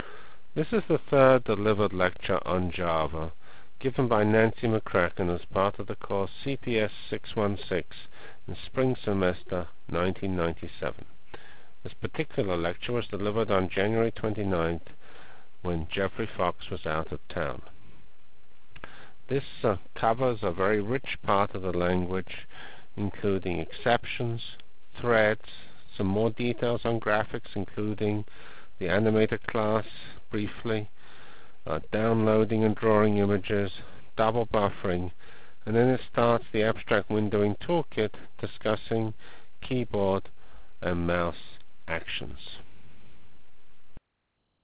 Foil 1 CPS 616 Java Lectures